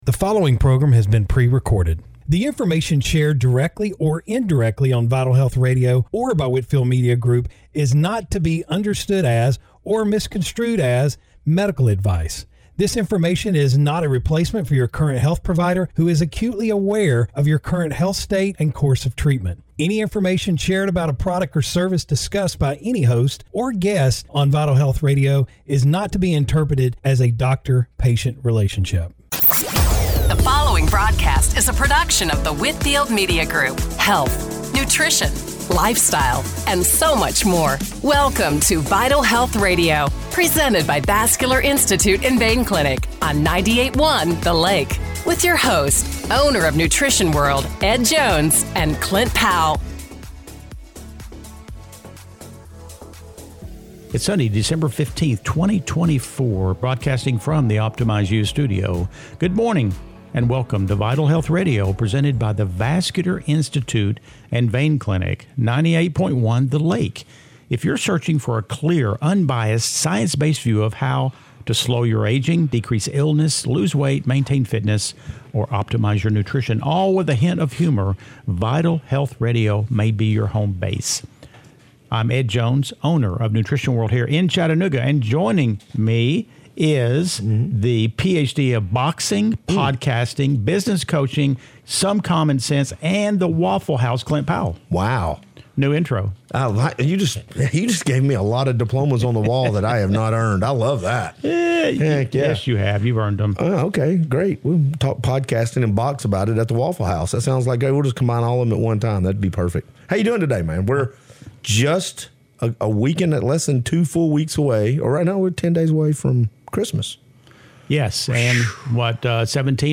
Radio Show – December 14, 2024 - Vital Health Radio